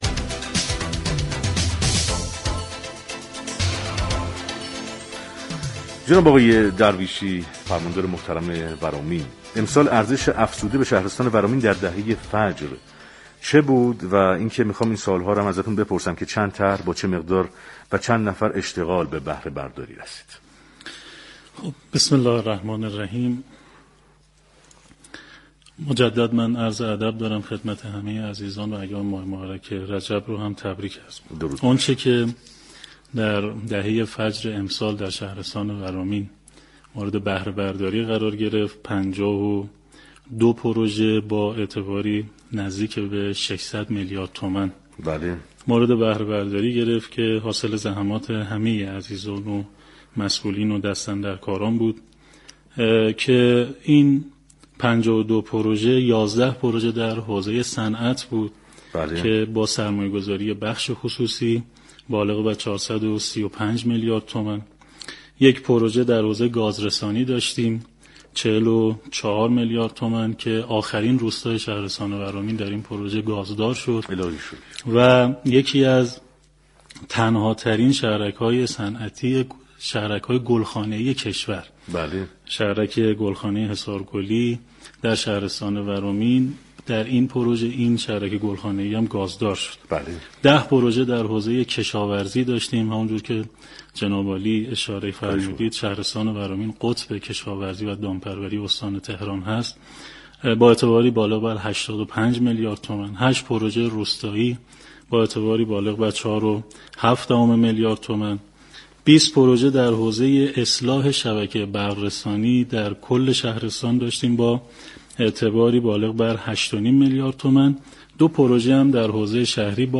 فرماندار ورامین در گفتگو با برنامه پل مدیریت از افتتاح 52 پروژه در دهه فجر خبر داد و گفت: با حمایت استاندار تهران و تخصیص بودجه لازم، قطار حومه ورامین در سال آینده افتتاح و مورد بهره برداری قرار می گیرد.